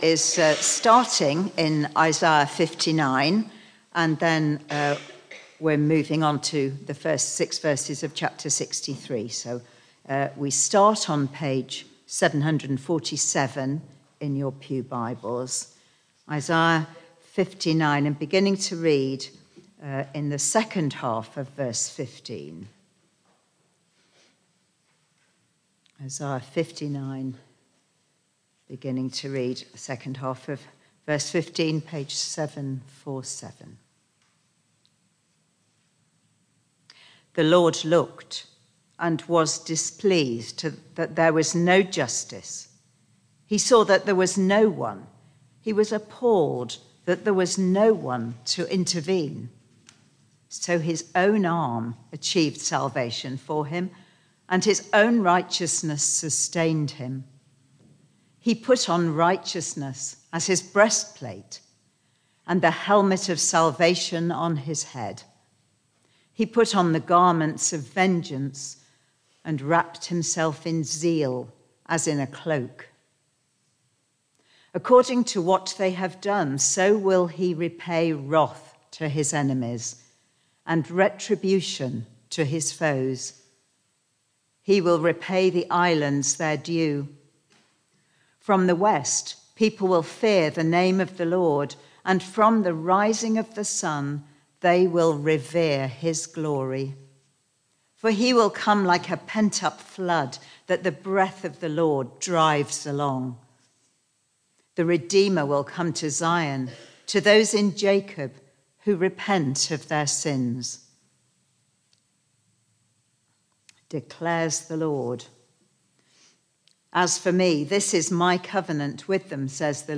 Media for Barkham Morning Service on Sun 30th Apr 2023 10:00
Sermon